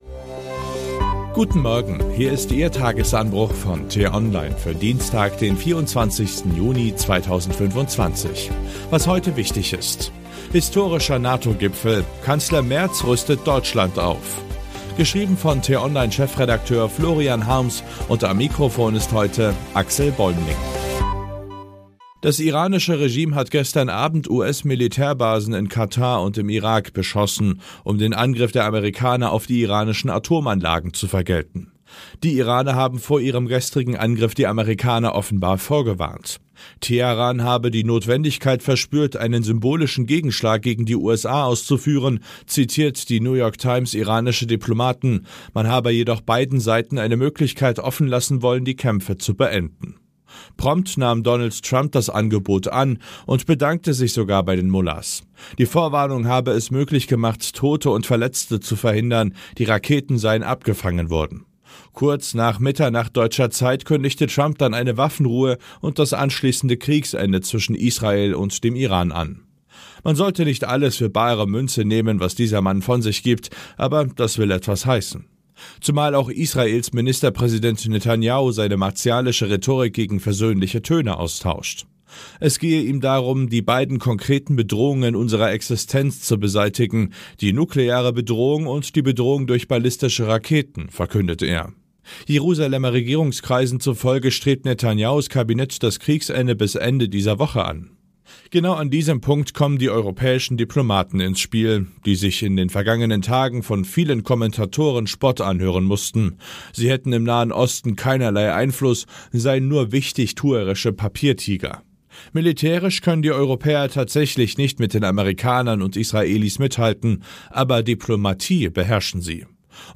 Der Nachrichtenpodcast von t-online zum Start in den Tag.